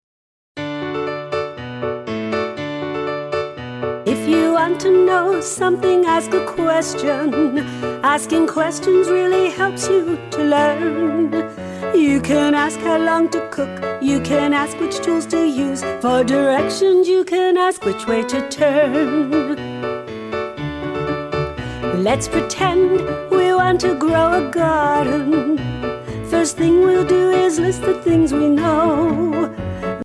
Learn science with these fun and upbeat songs!